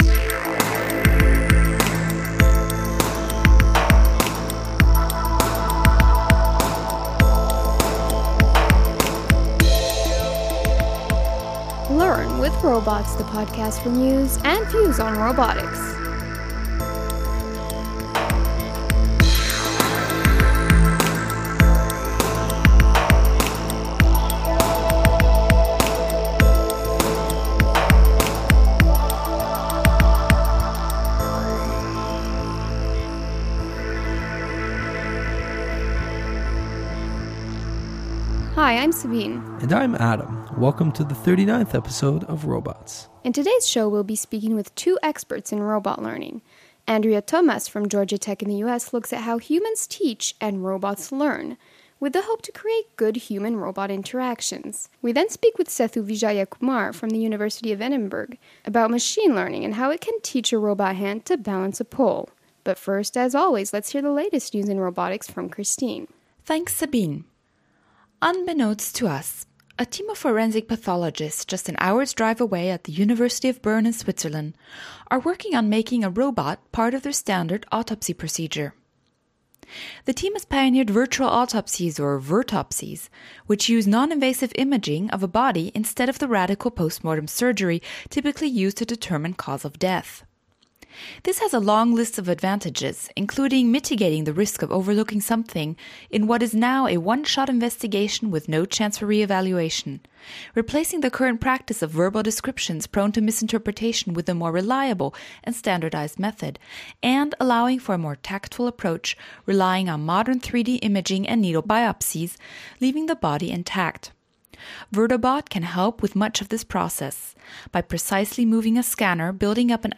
In this episode we speak with two experts in robot learning.